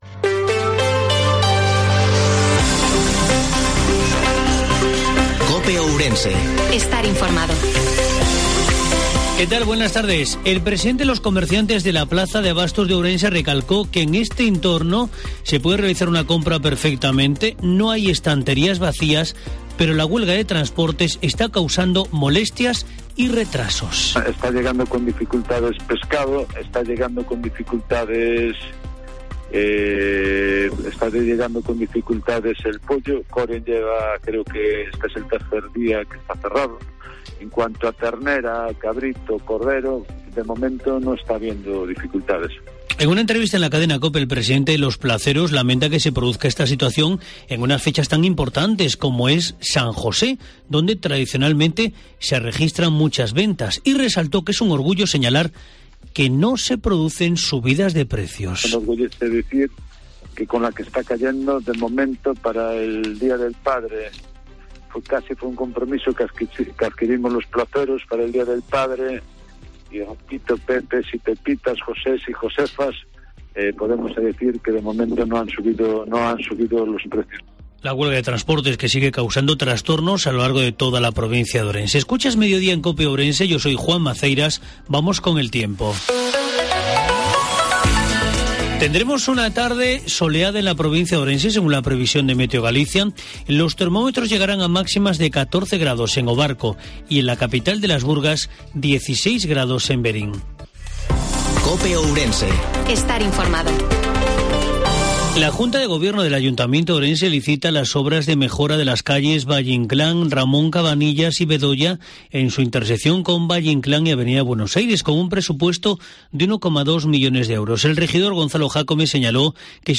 INFORMATIVO MEDIODIA COPE OURENSE 17/03/2022